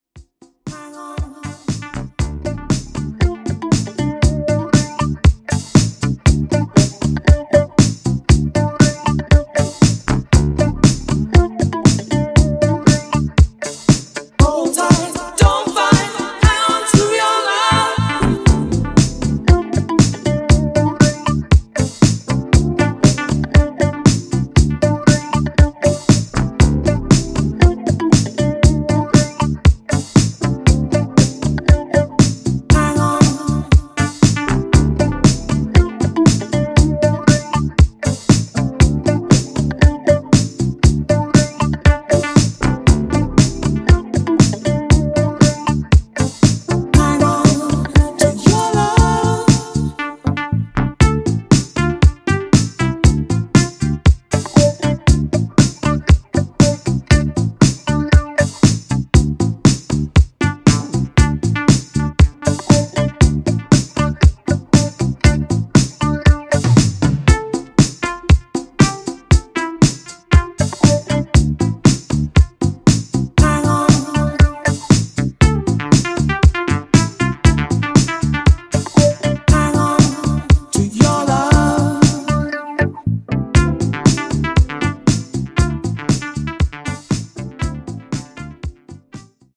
a very effective light chuggy house roller